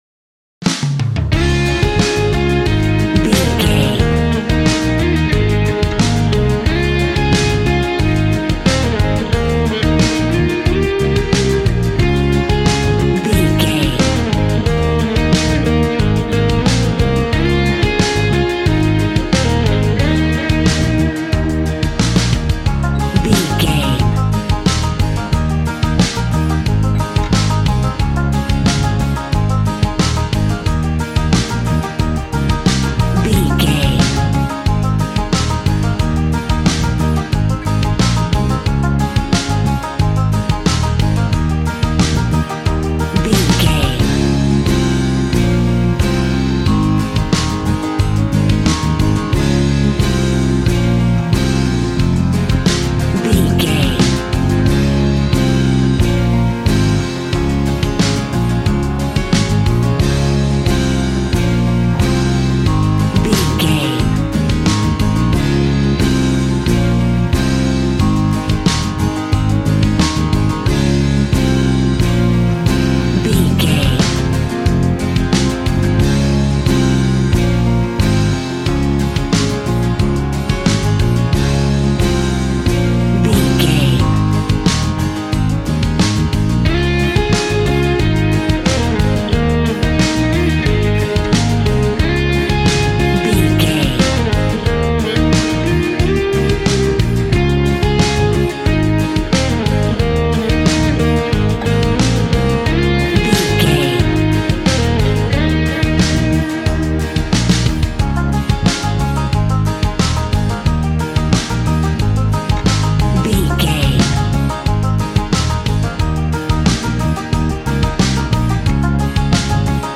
Ionian/Major
A♭
groovy
happy
electric guitar
bass guitar
drums
piano
organ